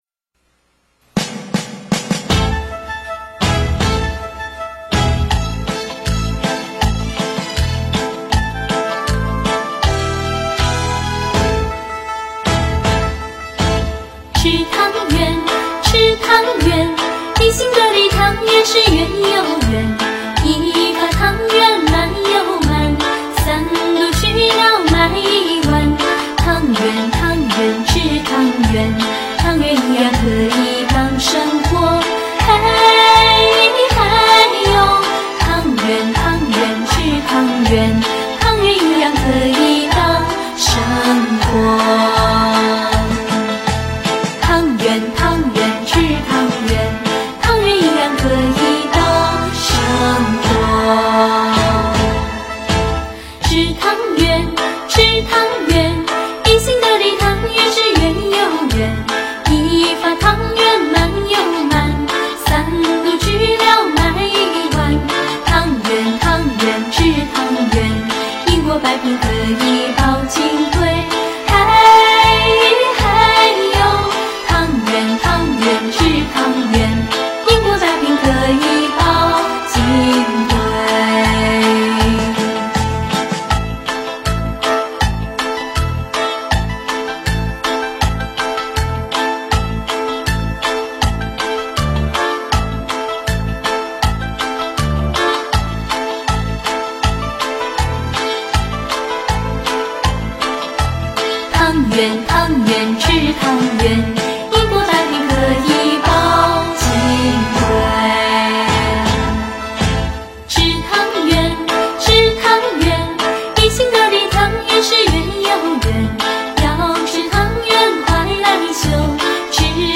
佛音 诵经 佛教音乐 返回列表 上一篇： 捉真性 下一篇： 云水逸 相关文章 月满西楼 月满西楼--古筝...